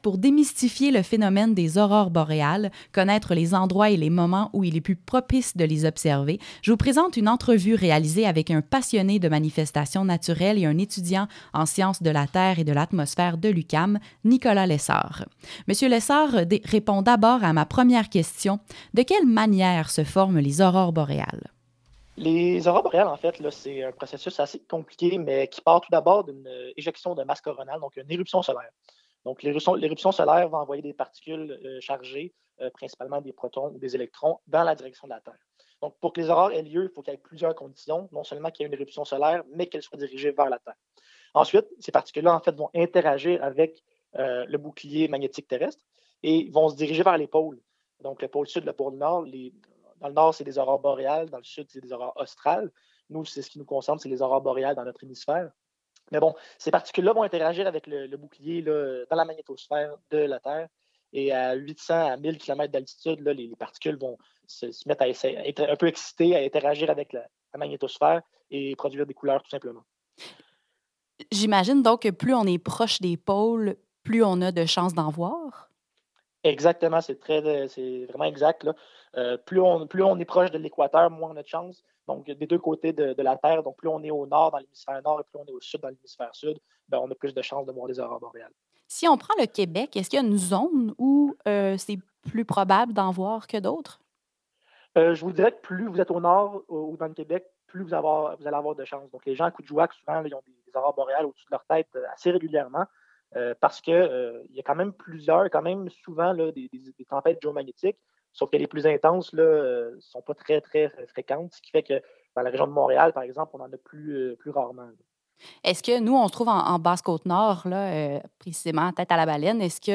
Aurore-boréale-segment-radio.mp3